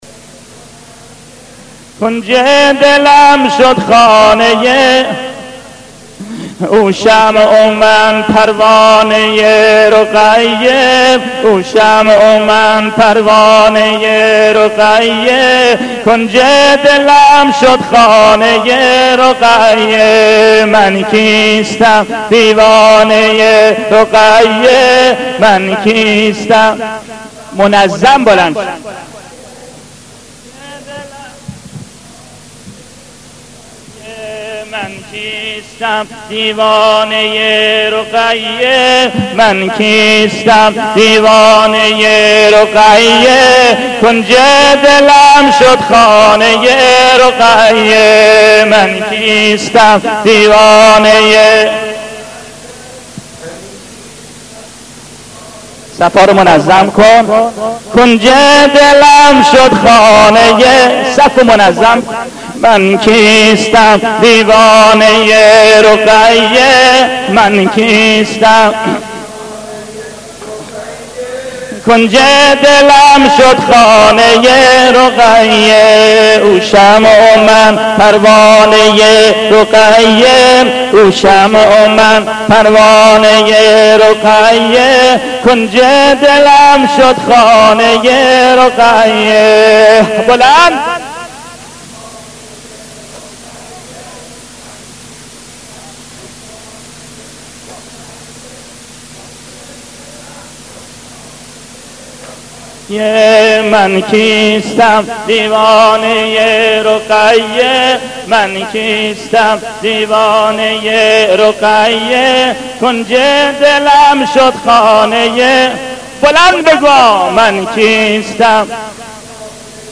نوحه قدیمی